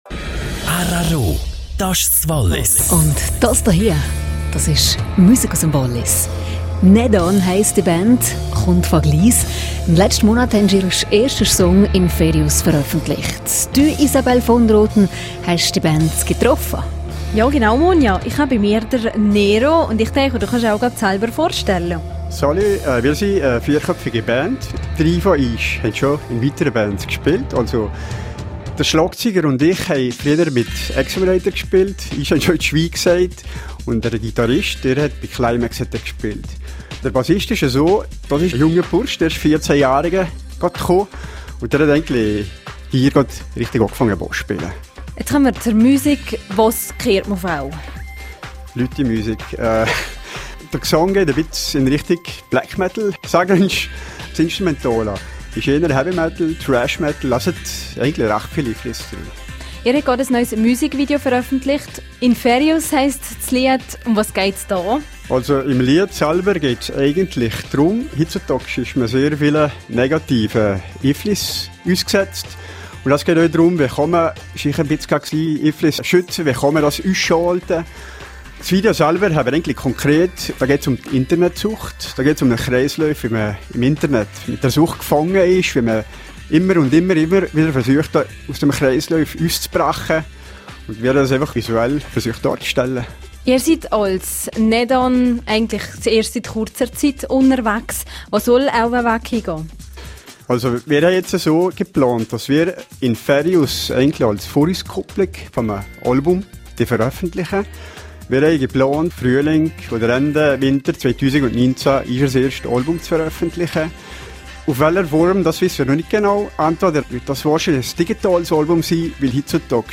Die neue Gliser Metalband Nedan veröffentlichte im September ihren ersten Videoclip. Nun stellt sich die Band im rro-Interview vor.